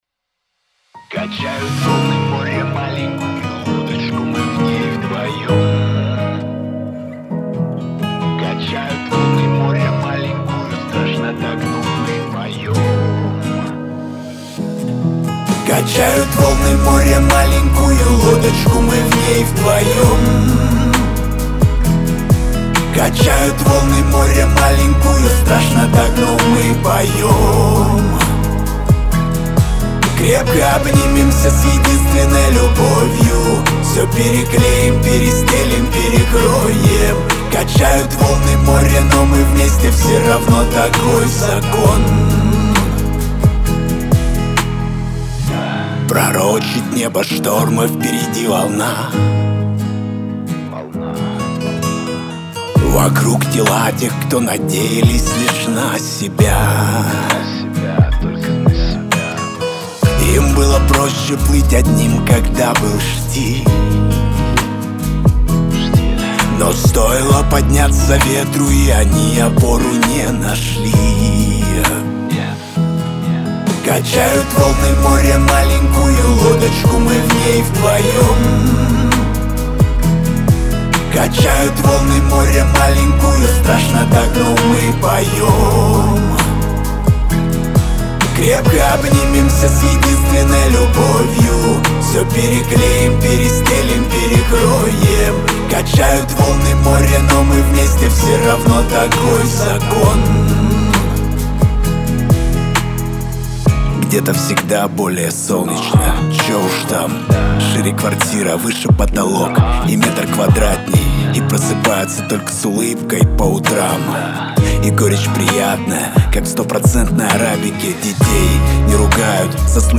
эстрада
ХАУС-РЭП